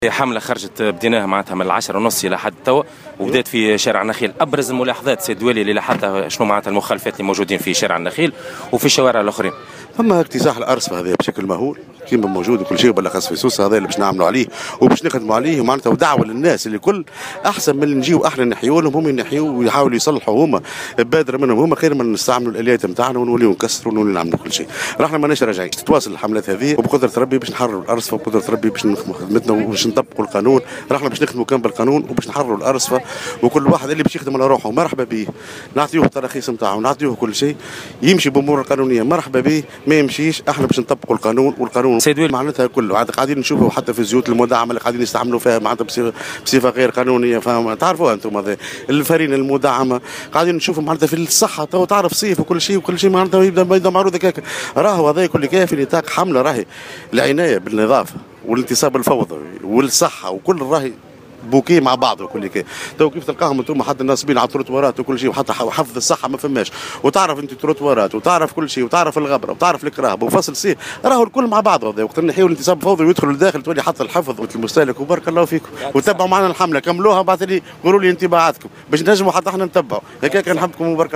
أكد والي سوسة عادل الشليوي في تصريح للجوهرة "اف ام" اليوم الثلاثاء 11 جويلية 2017 خلال حملة مشتركة نفذتها الشرطة البلدية و أعوان الإدارات الجهوية للصحة والتجارة بإشرافه أن الحملات على ظاهرة اكتساح الأرصفة ستتواصل دون هوادة .